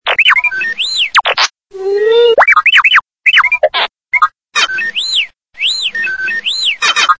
Savez-vous causer le R2D2 ?
Et comment dit-on “iTrafik c’est trop de la balle” si on souhaite se faire comprendre d’un R2D2 (chose désormais courante) ?